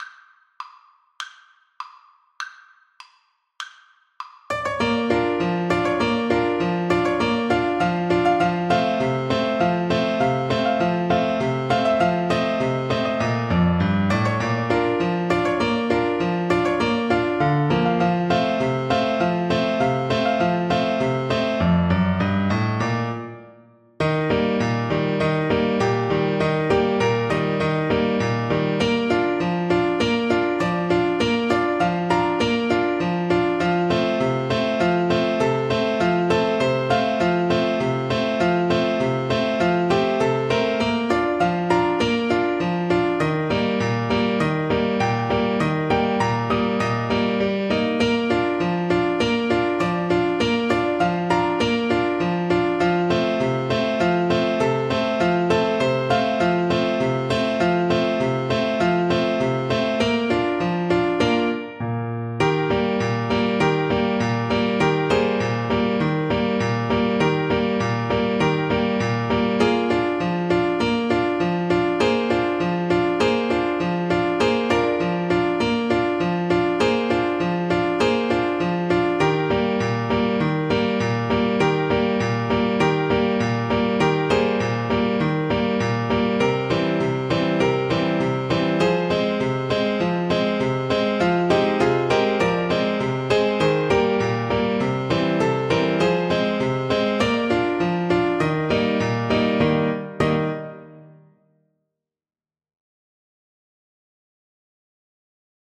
2/4 (View more 2/4 Music)
Bb4-Eb6
Polkas for Trumpet